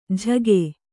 ♪ jhage